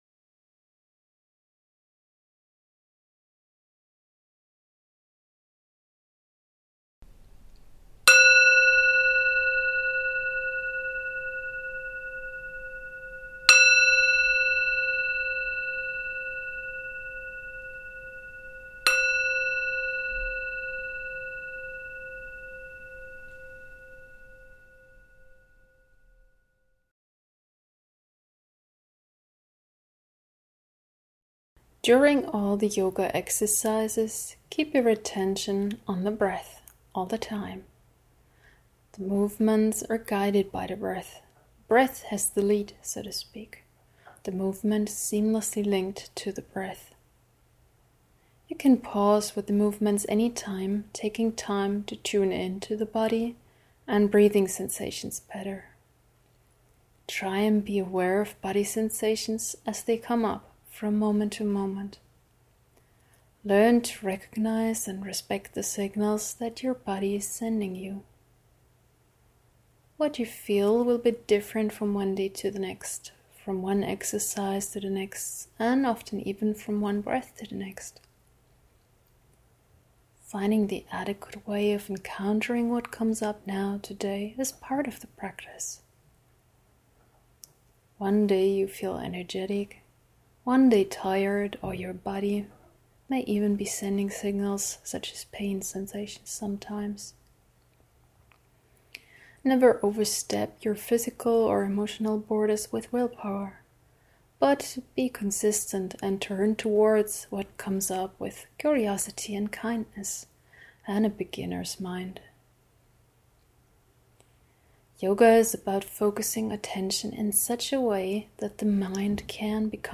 You can listen to the guided meditations here or download them to listen from your device – offline and anywhere.
Sitting meditation Sitting meditation – 10 minutes sitting meditation download Bodyscan Bodyscan – 40 minutes Bodyscan download Yoga Mindful Yoga – 30 minutes Yoga download